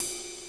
51ridcym.wav